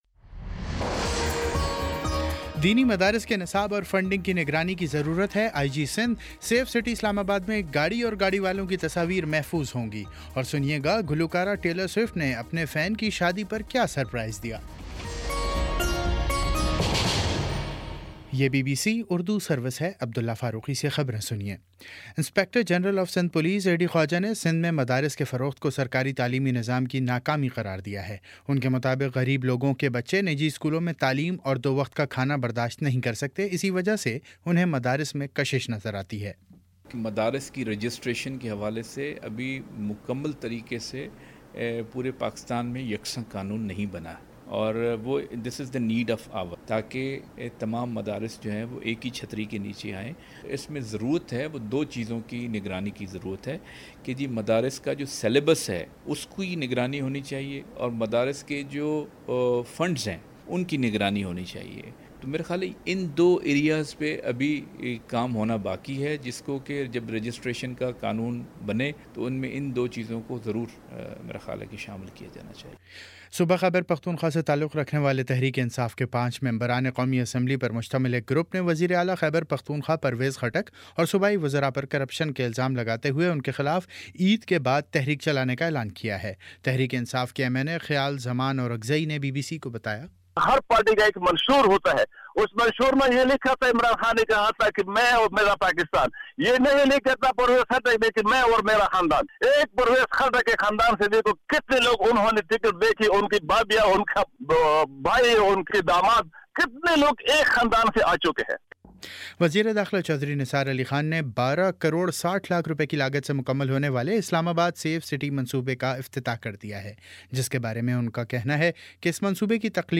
جون 06 : شام سات بجے کا نیوز بُلیٹن